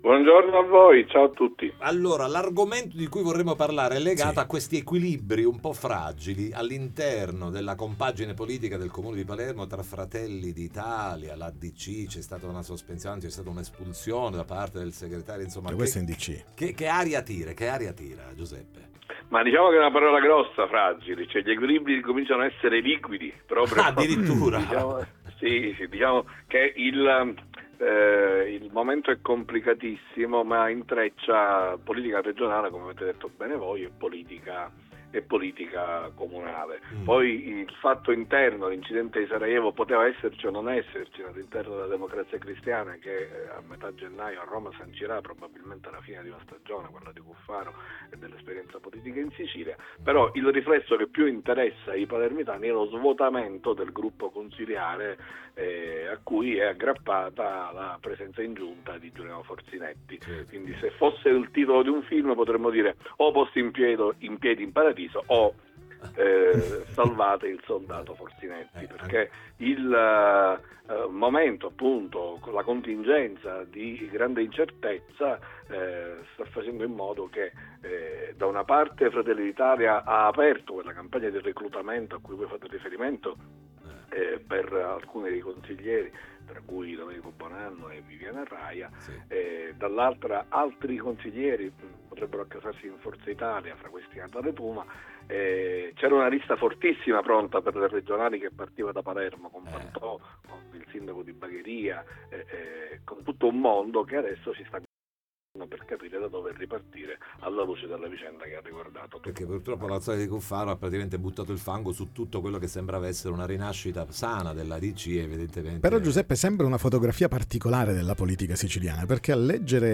Equilibri politici al Comune di Palermo Interviste Time Magazine 09/01/2026 12:00:00 AM